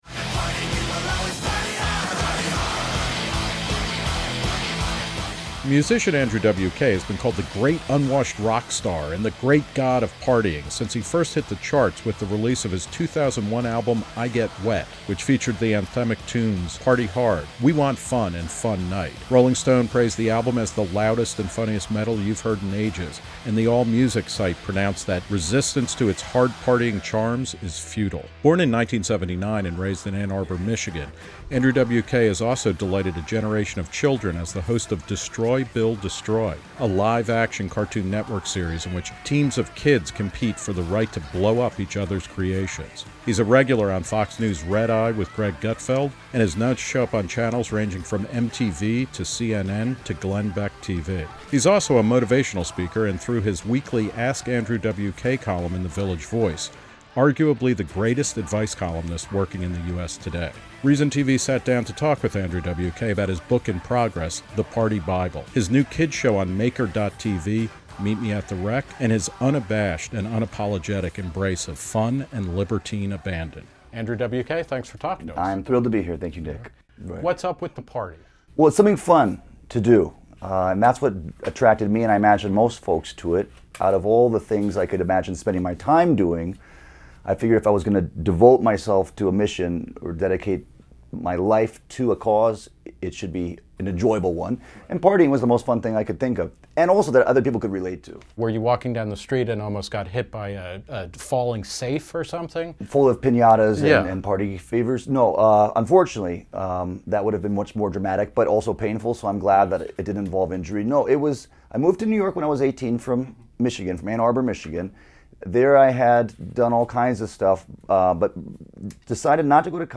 Q and A With Andrew W.K.: "A Role Model for Fun"